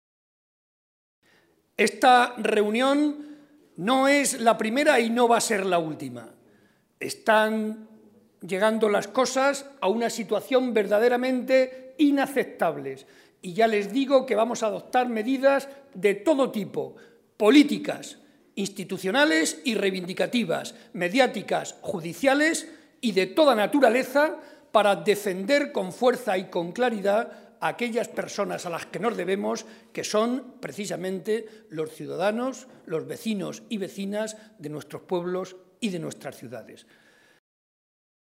Barreda-reunion_alcaldes_psoe-2.mp3